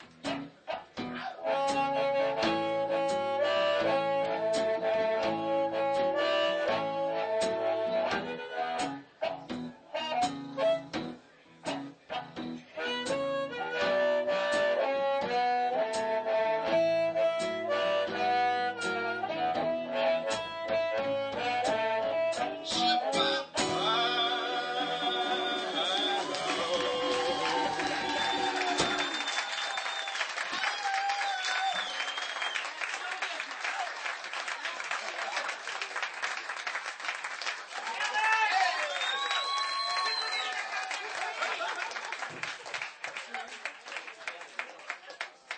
5th OTS Recital - Winter 2005